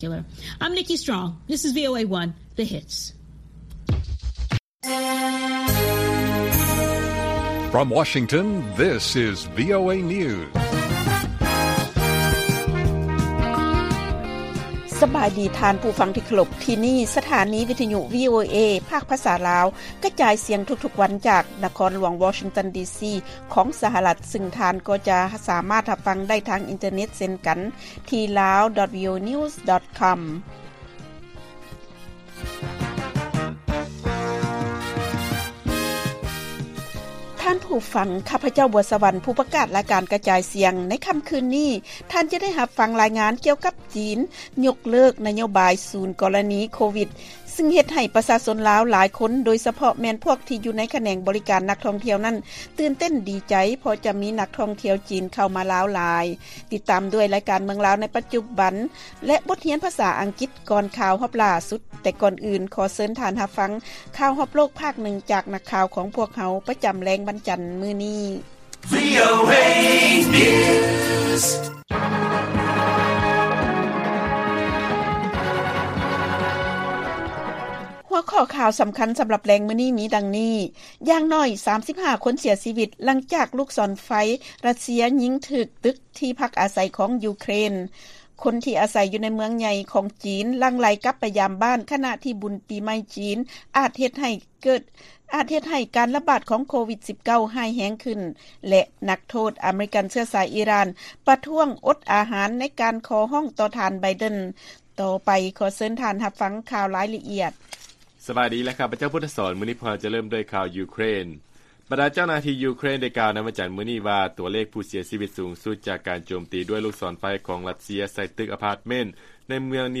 ລາຍການກະຈາຍສຽງຂອງວີໂອເອ ລາວ: ຢ່າງໜ້ອຍ 35 ຄົນເສຍຊີວິດ ຫຼັງຈາກລູກສອນໄຟ ຣັດເຊຍ ຍິງຖືກຕຶກທີ່ພັກອາໄສຂອງ ຢູເຄຣນ